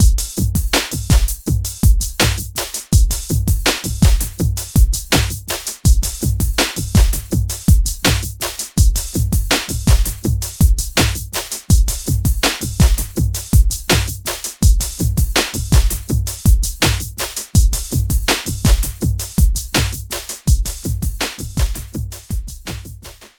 4声の美しいハーモニーとリズミカルなディレイを生み出す、直感的なピッチシフター
Quadravox | Drums | Preset: Inversions
Elec-Drums-Inversions.mp3